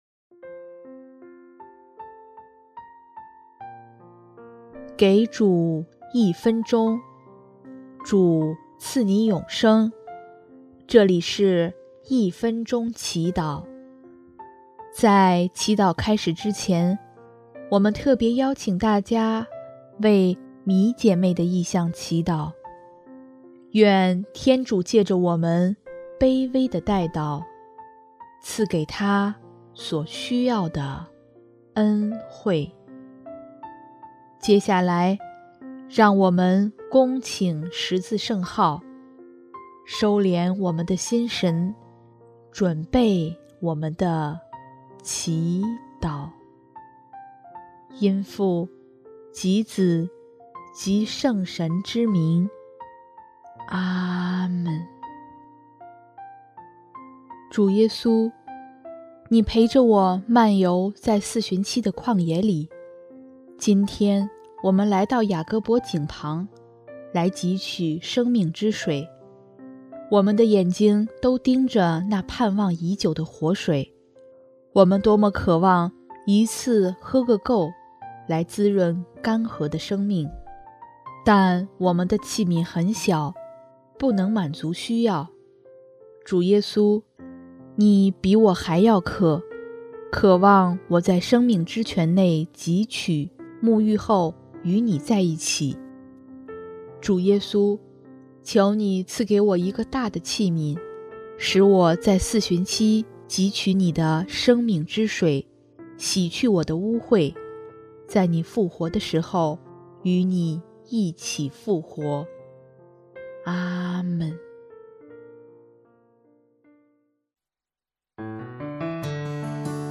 【一分钟祈祷】|3月3日 汲取生命之水，滋润干涸生命